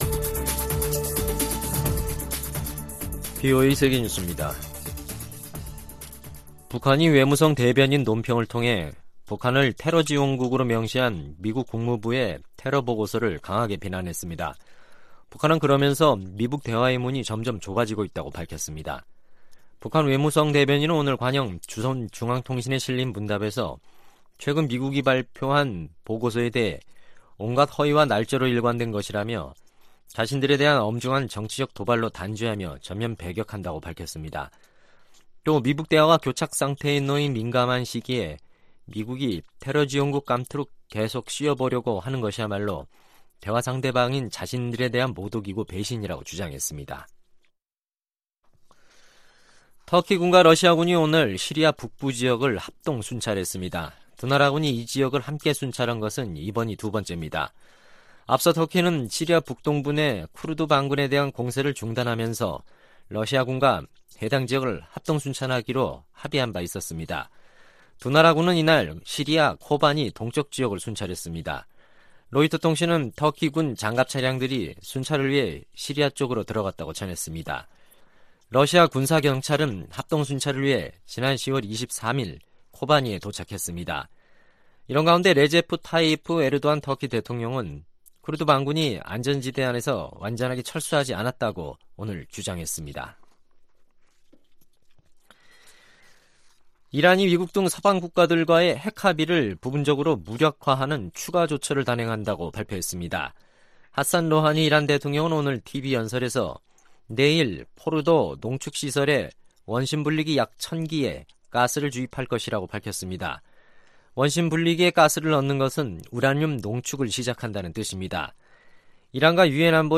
VOA 한국어 간판 뉴스 프로그램 '뉴스 투데이', 2019년 11월 5일 3부 방송입니다. 미-북 비협상에 진전이 없는 가운데, 미 의회에 안에서는 북한 문제에 대한 ‘다음 단계’를 논의해야 한다는 목소리가 높아지고 있습니다. 미국의 전문가들은 미국과 북한이 연말 이전에 비핵화 협상에서 큰 진전을 이루기 어려워 보인다고 전망했습니다.